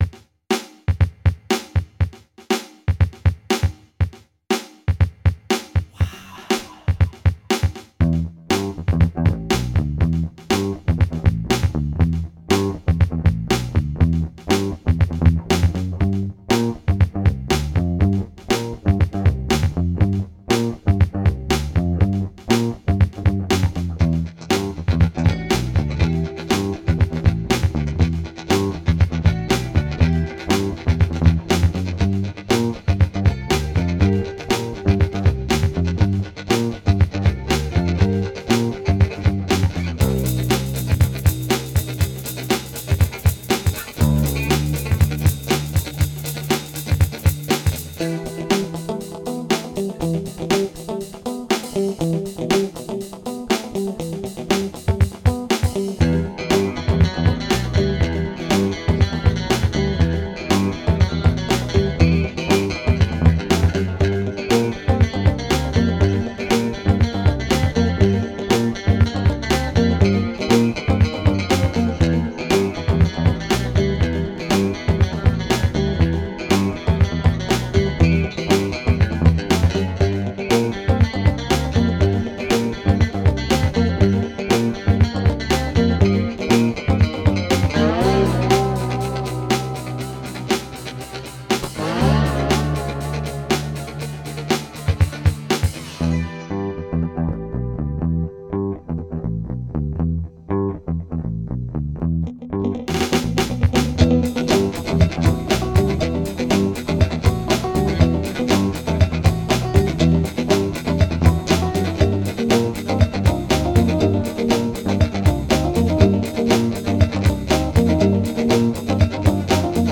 A short rock instrumental.